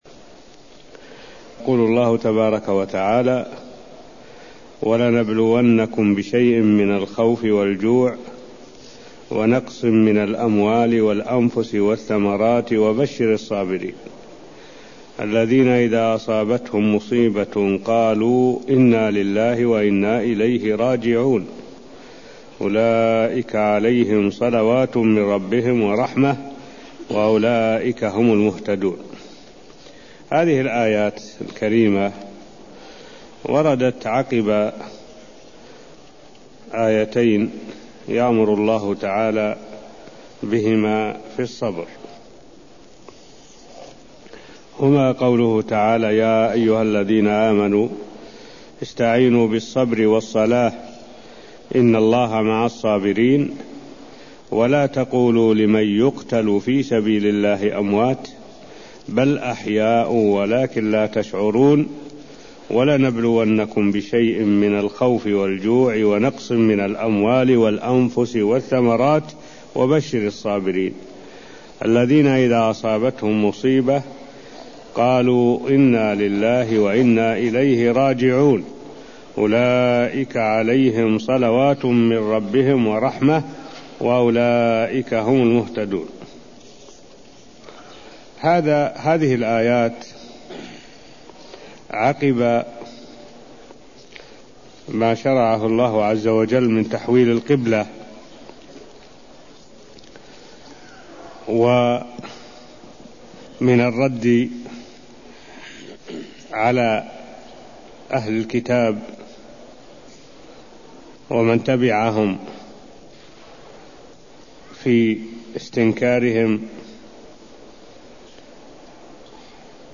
المكان: المسجد النبوي الشيخ: معالي الشيخ الدكتور صالح بن عبد الله العبود معالي الشيخ الدكتور صالح بن عبد الله العبود تفسير الآيات155ـ158 من سورة البقرة (0081)